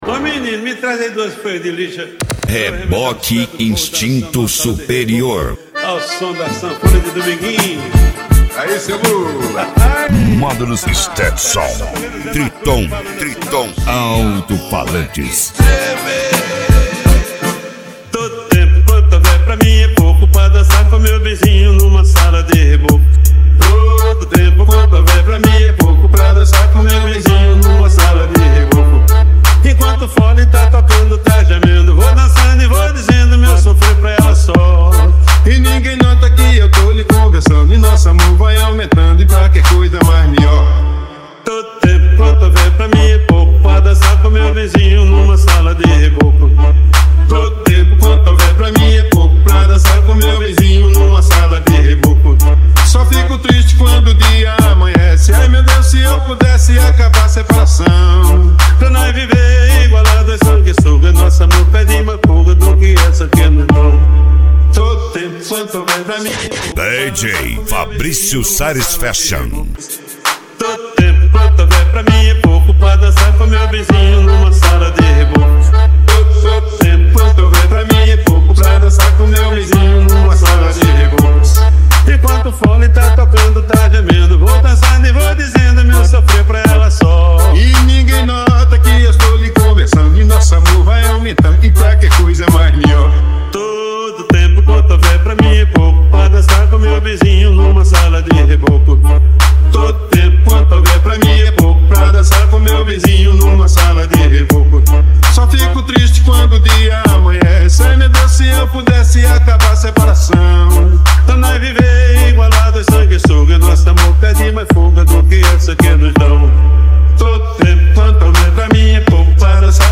Bass
Mega Funk